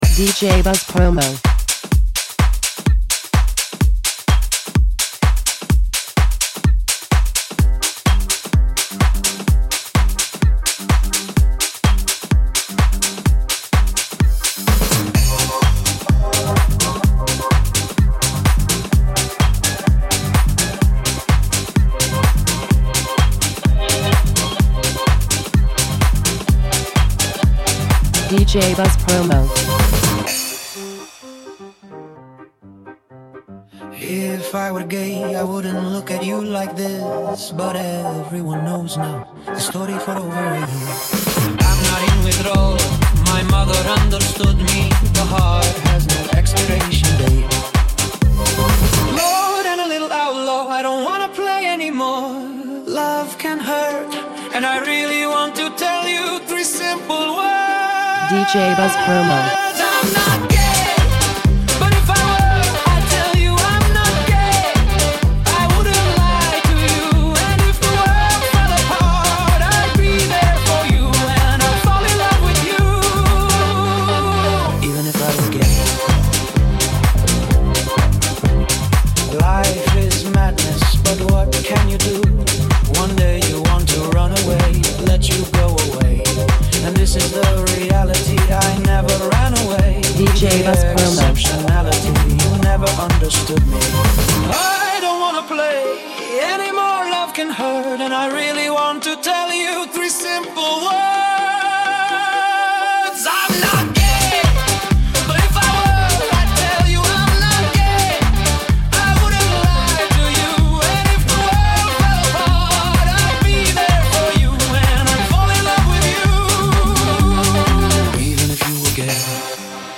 laced with samples from '30s and '40s jazz
infuses a soulful, jazz, inclusive vibe